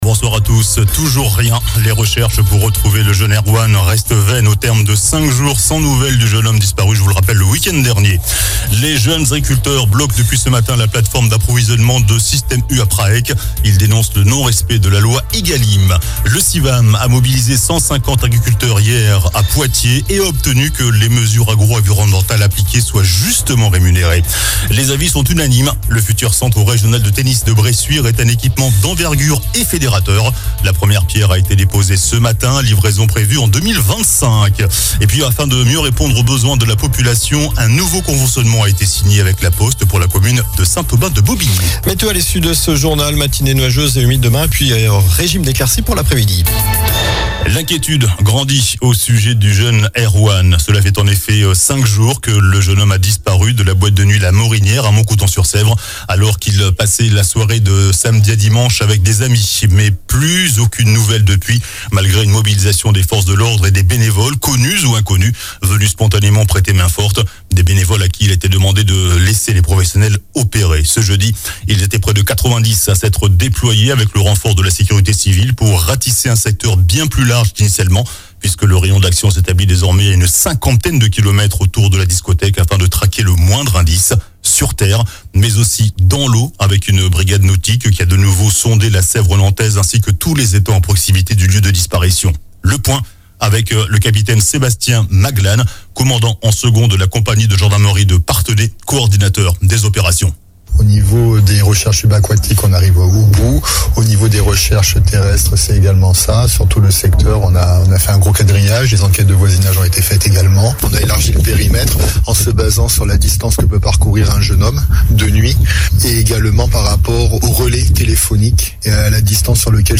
JOURNAL DU JEUDI 15 FEVRIER ( SOIR )